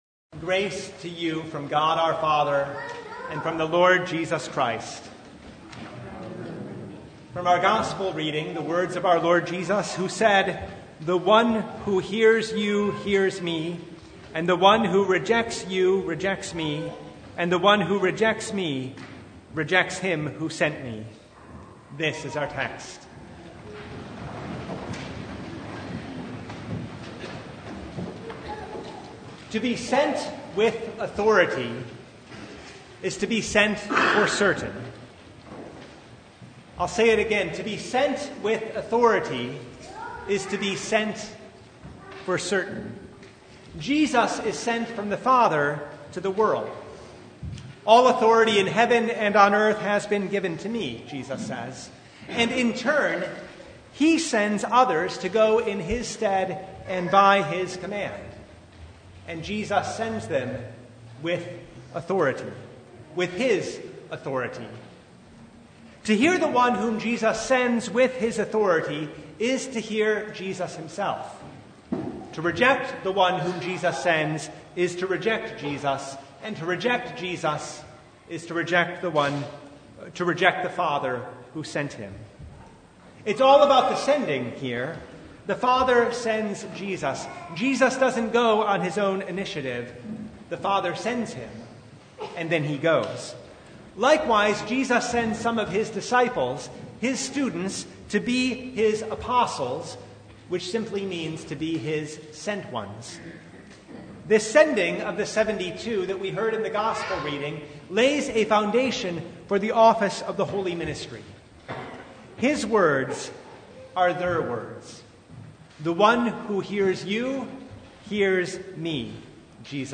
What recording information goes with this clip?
Passage: Luke 10:1-20 Service Type: Sunday